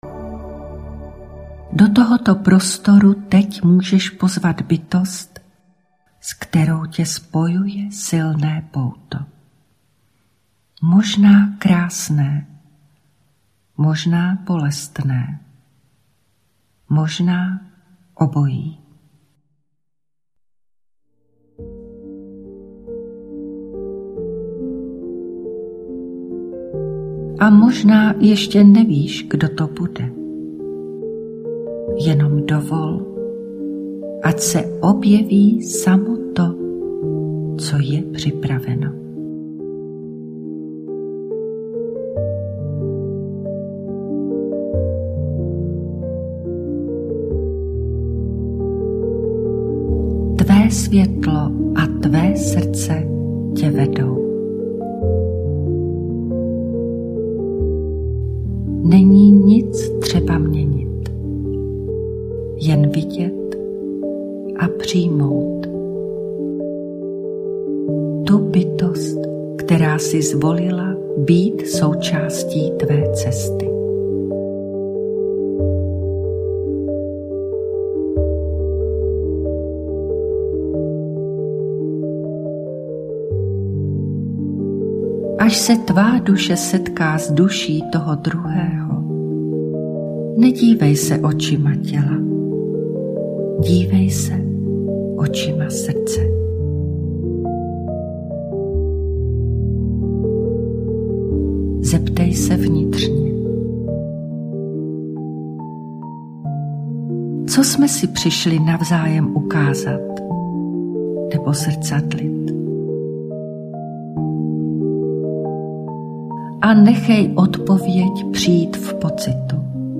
• Uklidňující vedení pro vstup do hlubšího stavu vědomí
• Jemnou léčivou hudbu, která podpoří celý proces
Doprovázeno jemnou, léčivou hudbou AShamaluev Music